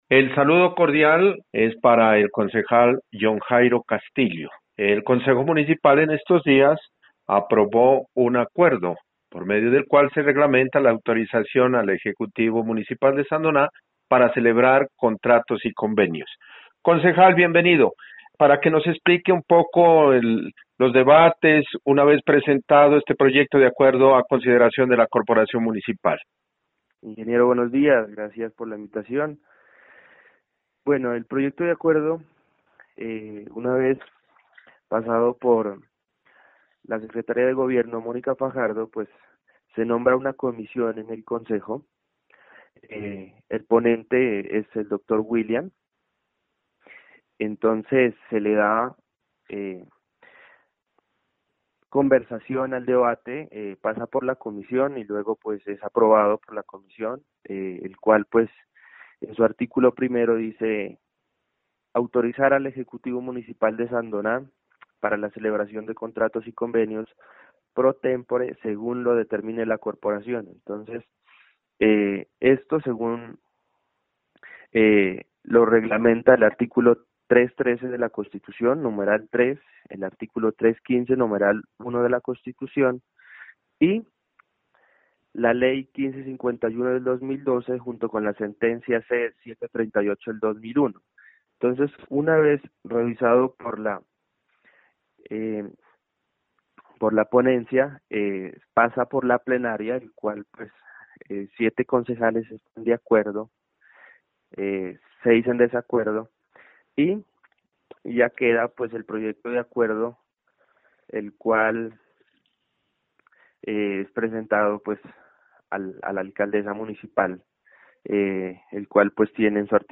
Entrevista con el concejal John Jairo Castillo: